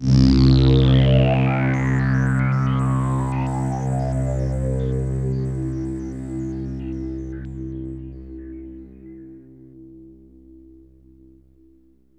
AMBIENT ATMOSPHERES-5 0005.wav